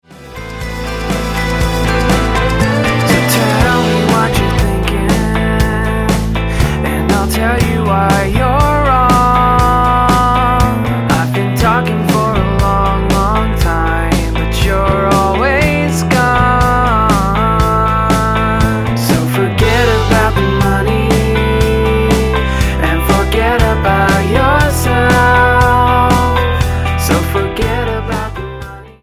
Roots/Acoustic
Style: Rock